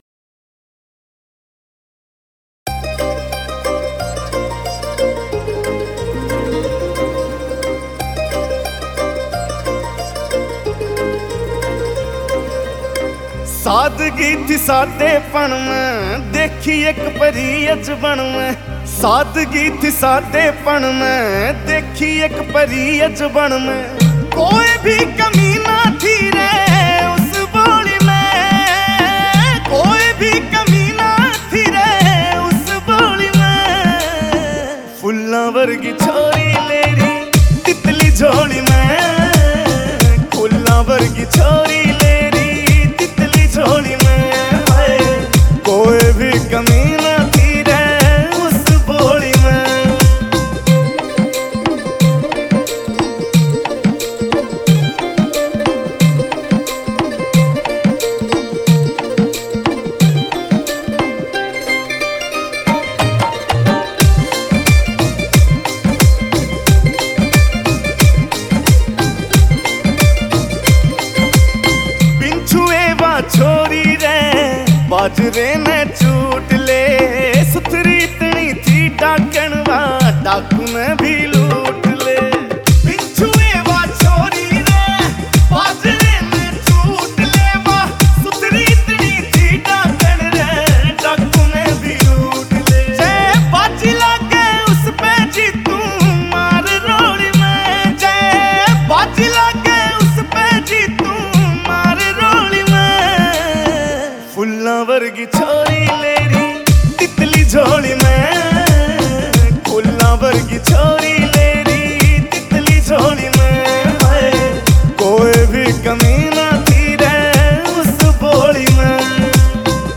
haryanvi songs 2025 mp3 download